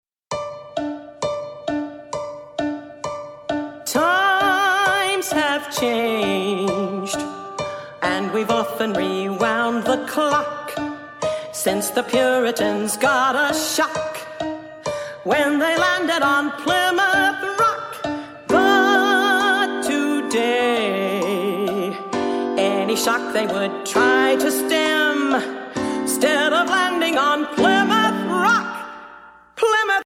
Voicing: PVG Collection